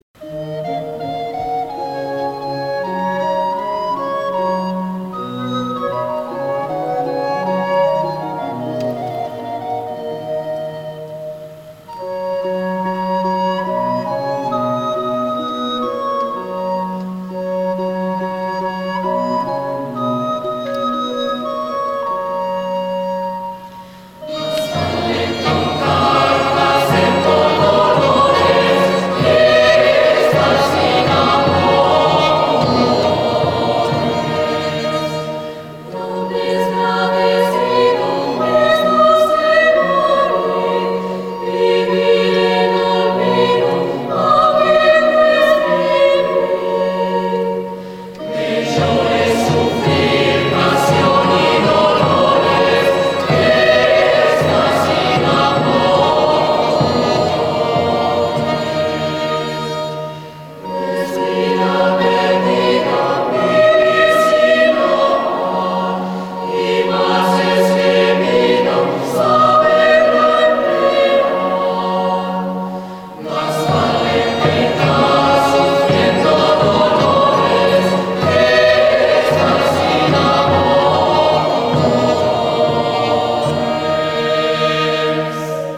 MÚSICA DEL RENAIXEMENT.
CANT CORAL POLIFÒNIC